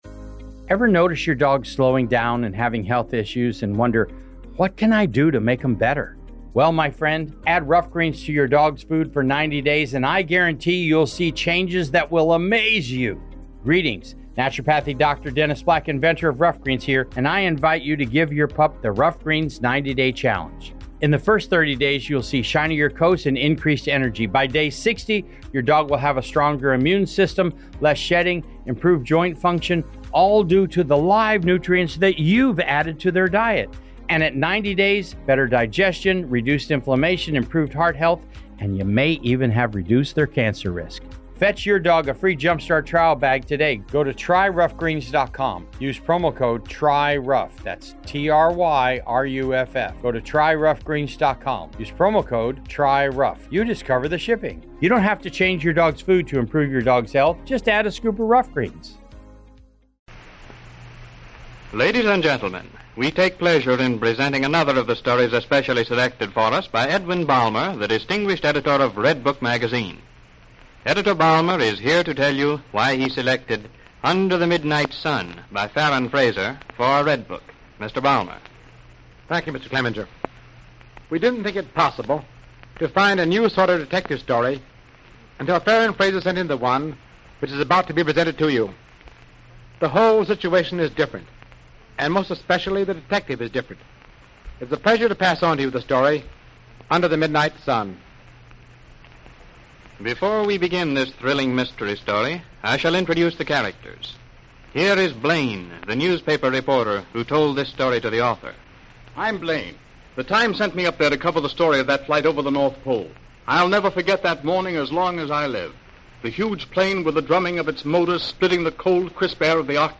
"Redbook Dramas" was a popular radio series in the early 1930s that brought the stories from Redbook Magazine to life over the airwaves.